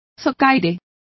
Complete with pronunciation of the translation of lees.